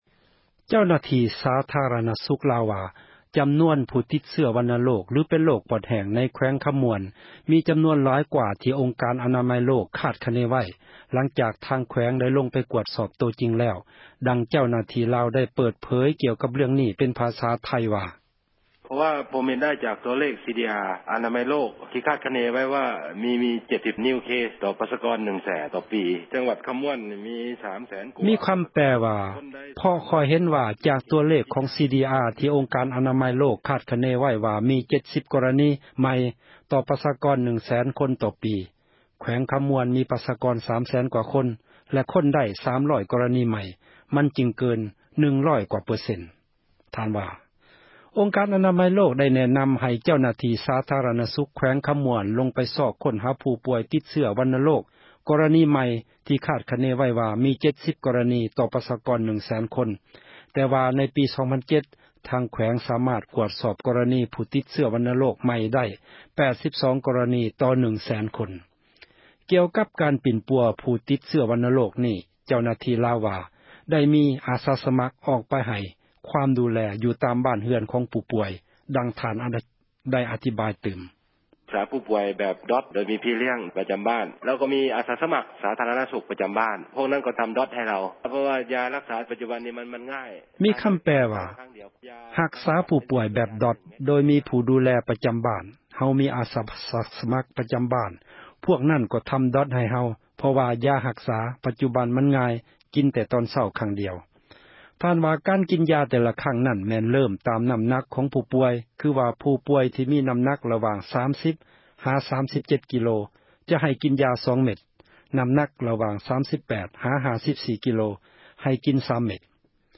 ເຈົ້າໜ້າທີ່ ສາທຣະນະສຸກ ລາວ ວ່າ: ຈໍານວນ ຜູ້ຕິດເຊື້ອ ວັນະໂຣກ ຫລື ເປັນ ໂຣກປອດແຫ້ງ ໃນ ແຂວງ ຄໍາມ່ວນ ມີ ຈໍານວນ ຫລາຍກ່ວາ ທີ່ ອົງການ ອານາໄມ ໂລກ ຄາດຄະເນ ໄວ້ ຫລັງຈາກ ທາງ ແຂວງ ໄດ້ ລົງ ໄປ ກວດສອບ ໂຕຈິງ ແລ້ວ, ດັ່ງ ເຈົ້າໜ້າທີ່ ລາວໄດ້ ເປີດເຜີຽ ກ່ຽວກັບ ເຣື້ອງ ນີ້ ເປັນ ພາສາໄທ ໂດຍມີ ຄຳແປ ດັ່ງນີ້: